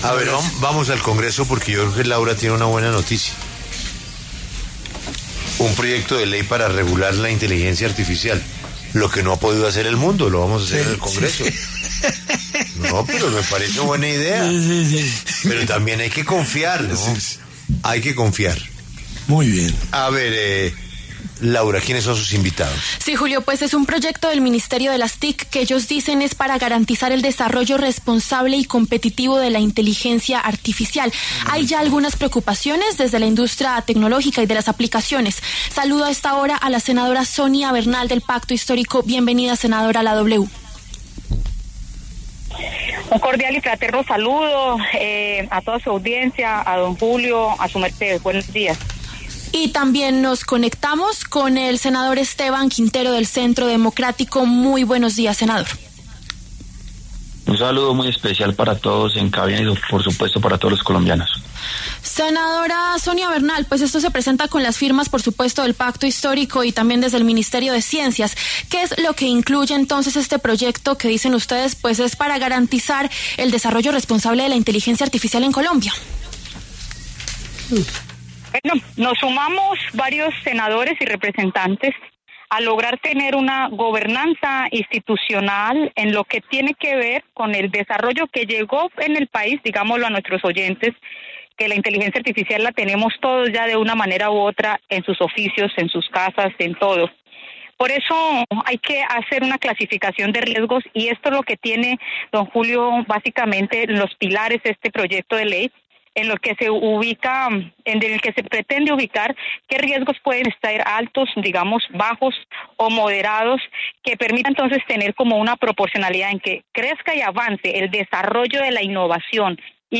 Los senadores Sonia Bernal, del Pacto Histórico, Esteban Quintero, del Centro Democrático, pasaron por La W.